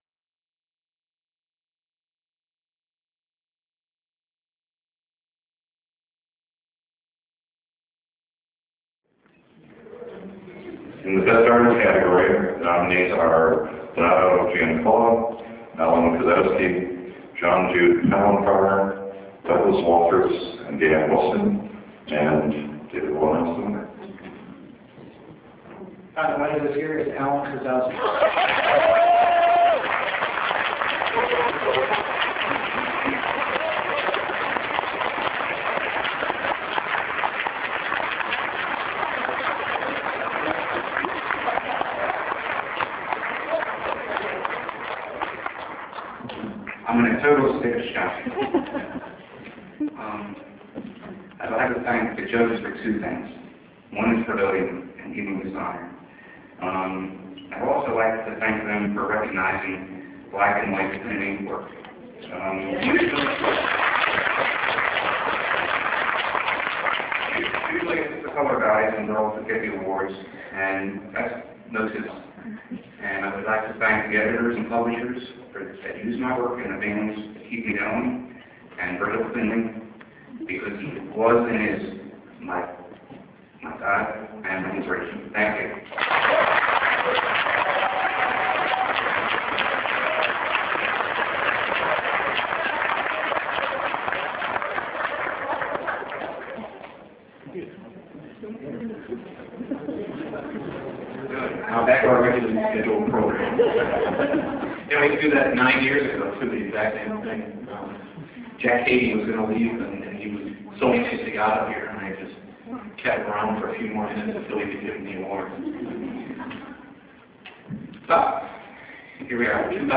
The 2002 World Fantasy Convention was held in Minneapolis Minnesota at the Hilton Towers. The Convention ran from October 31 - November 3.